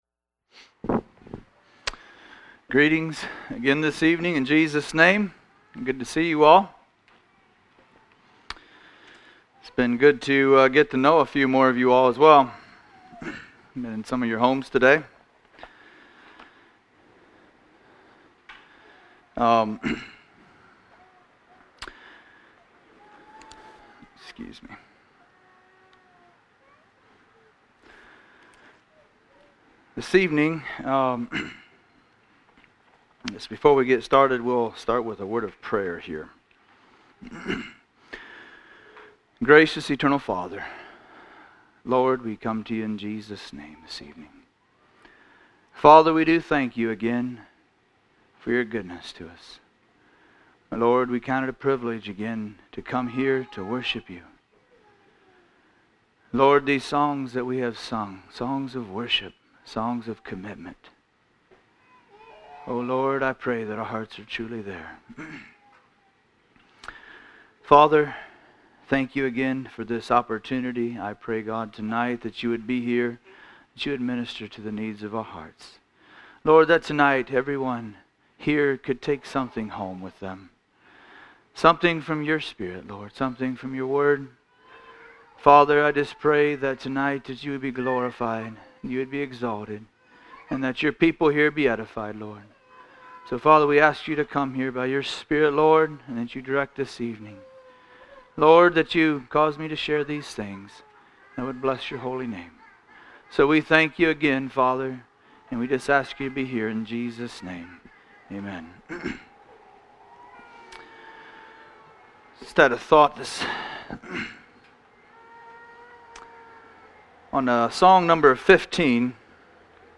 Series Tent Meetings 2022